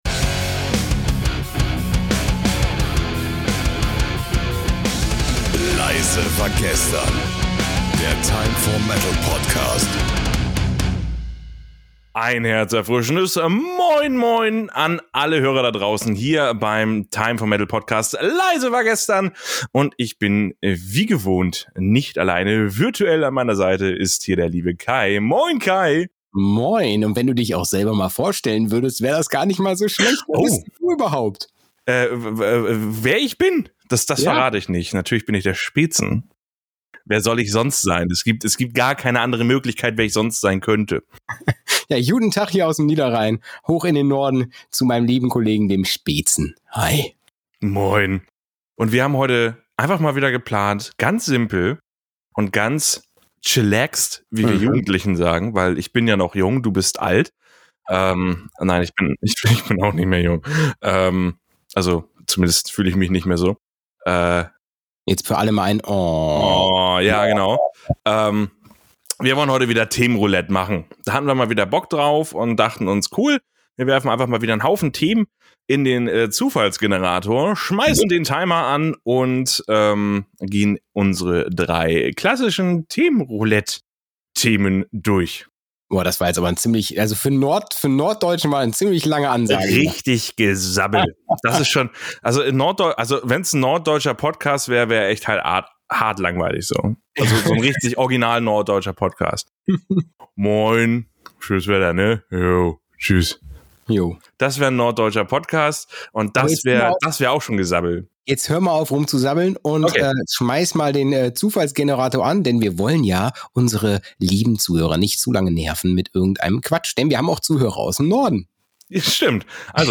Themenroulette - Die Spielregeln Pro Folge werden per Zufallsgenerator drei Themen gewählt, welche dann von den beiden Moderatoren innerhalb von exakt zehn Minuten behandelt werden. Nach zehn Minuten wird die Unterhaltung gestoppt und das nächste Thema wird behandelt.